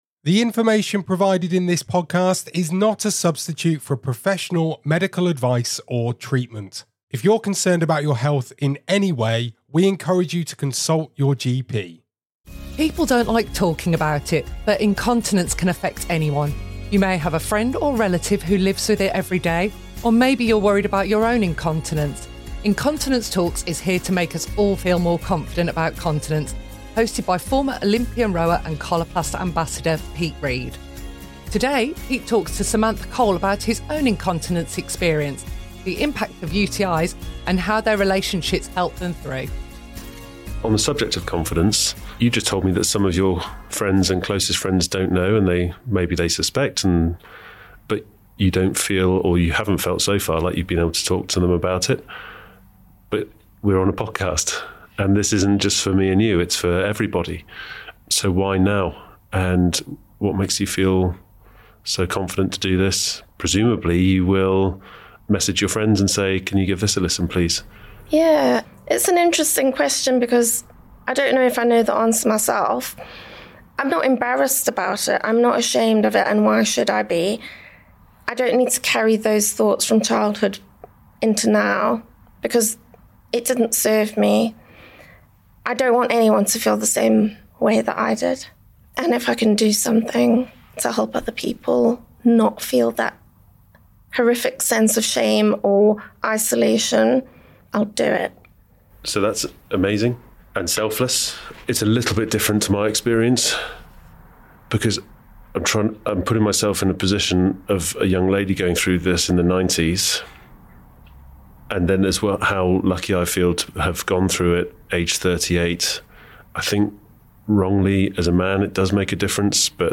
Retired British Olympic rower, Pete Reed continues his chat